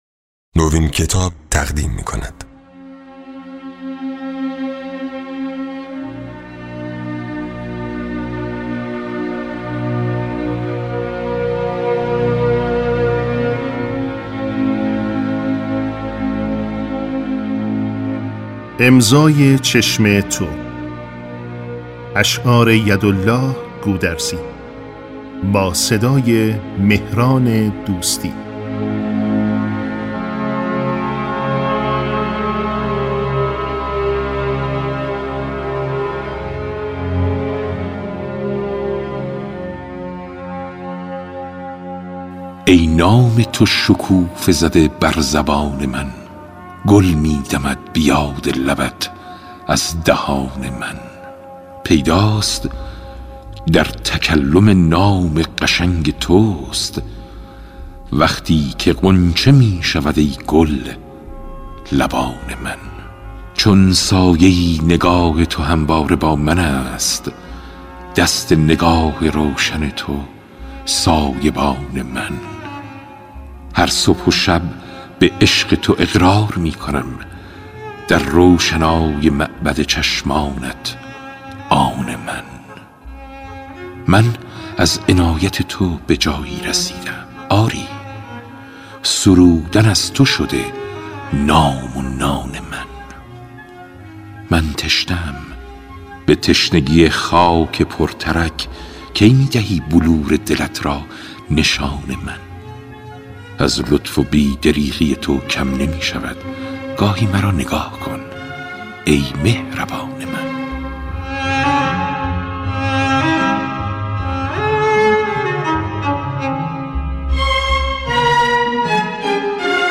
گفتگوی رادیویی مهران دوستی با «هوشنگ مرادی کرمانی»
توانمندی او در اجرا صدای ششدانگ و شفاف، انعطاف صدا و آگاهی او در زمینه‌های هنری باعث شده بود که مهران دوستی گوینده‌ای کم نظیر باشد و خاطره‌های بسیاری برای مردم این سرزمین بسازد.